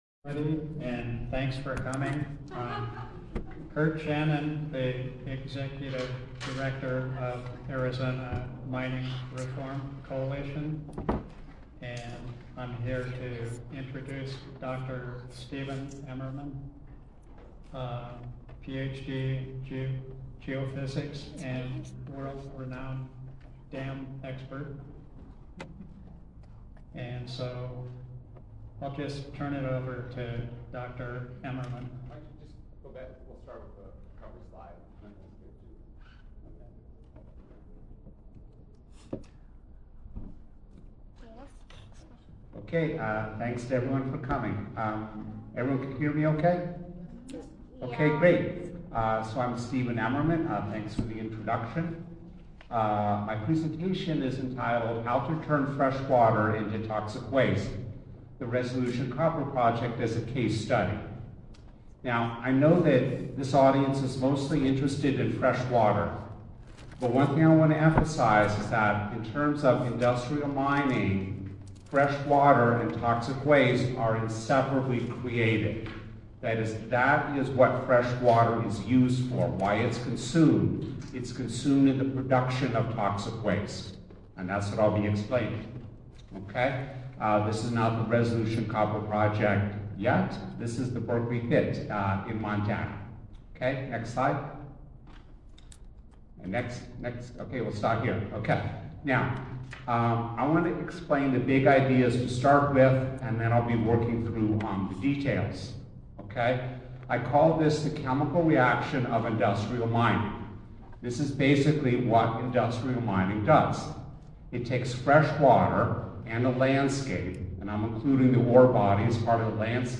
San Tan public meeting about water impacts of proposed Resolution Copper mine
Audio of presentation including Q&A.